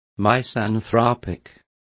Complete with pronunciation of the translation of misanthropic.